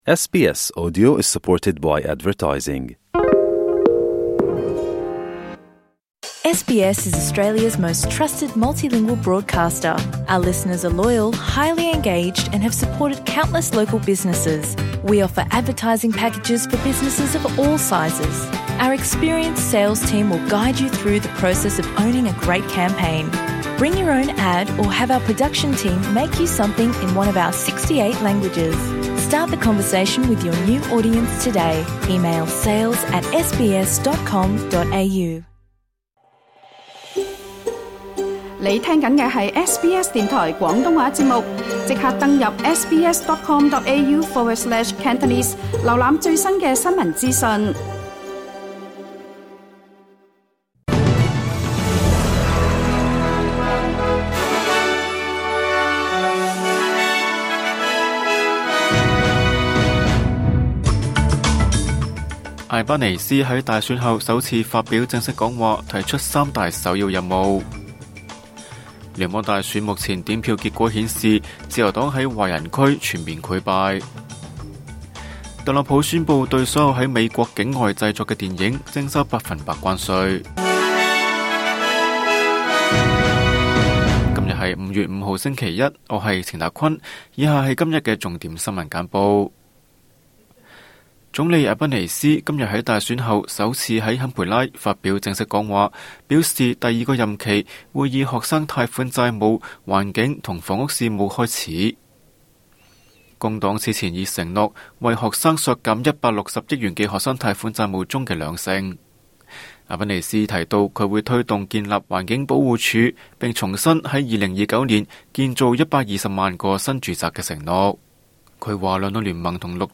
請收聽本台為大家準備的每日重點新聞簡報。
SBS晚間新聞（2025年5月5日） Play 05:40 SBS 廣東話晚間新聞 SBS廣東話節目 View Podcast Series 下載 SBS Audio 應用程式 其他收聽方法 Apple Podcasts  YouTube  Spotify  Download (5.19MB)  請收聽本台為大家準備的每日重點新聞簡報。